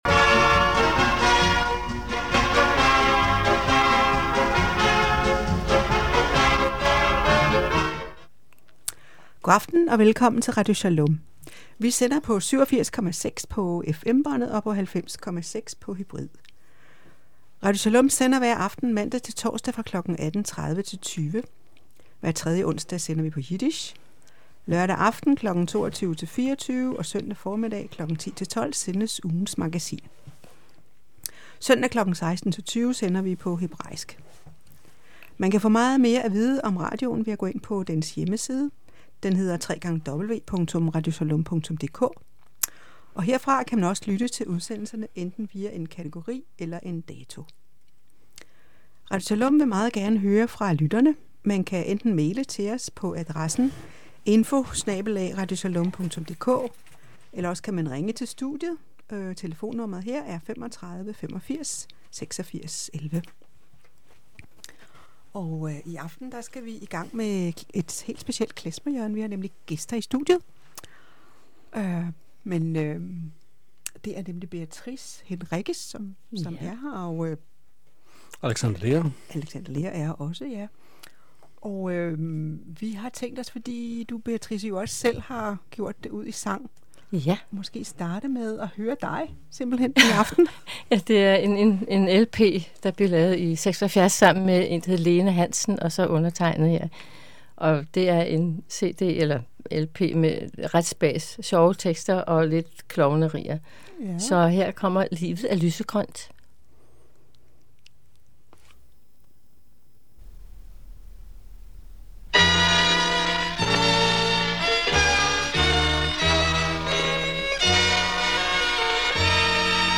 Klezmer hjørne